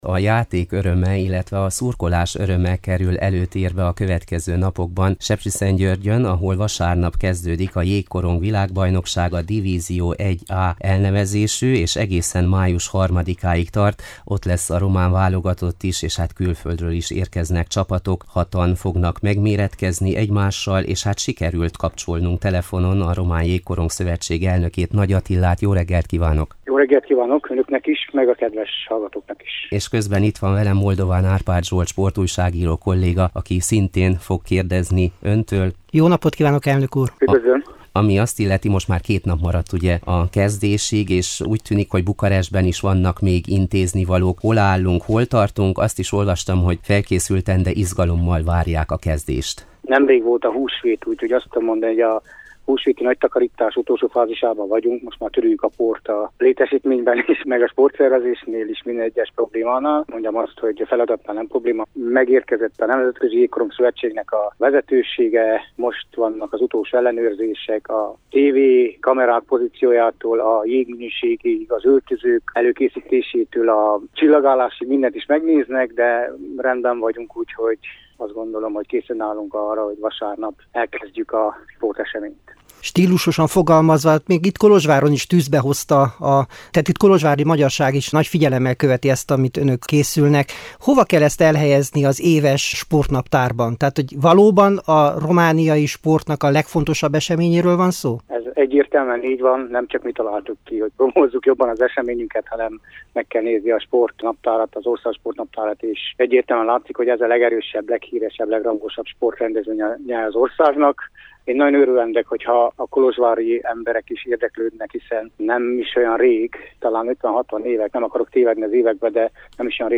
interjúja